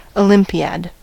olympiad: Wikimedia Commons US English Pronunciations
En-us-olympiad.WAV